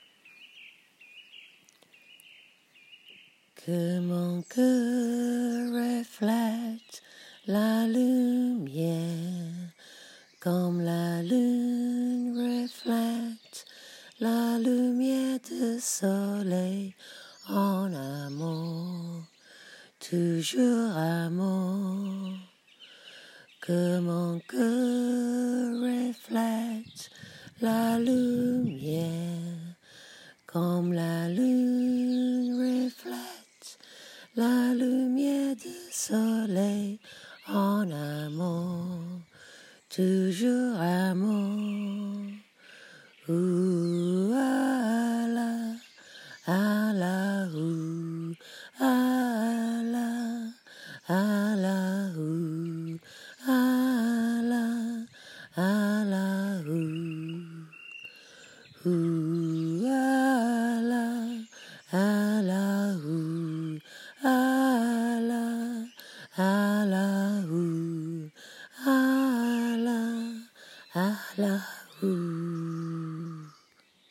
Une berceuse pour les enfants